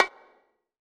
YBONGO HI.wav